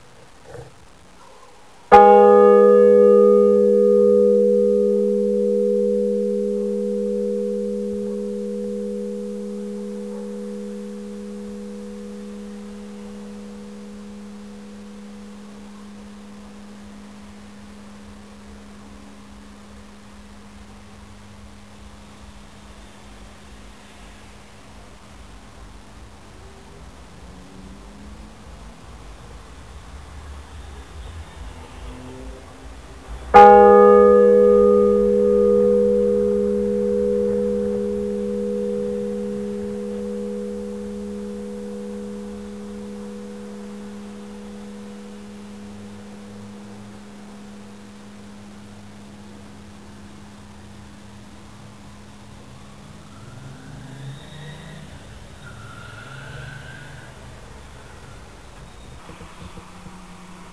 Die Glocke in dem folgenden Beispiel ist eine Stahlglocke in der St. Salvatoris-Kirche in Zellerfeld, Baujahr 1953.
Im Verlauf von einigen Sekunden verschwinden (Abb. 02) die oberen Partialtöne und am Ende bleibt nach 20 Sekunden nur noch der tiefste Ton (englisch hum = summen) übrig.
(Ganz rechts in der Analyse die Tonspur eines aufheulenden Motorrades.)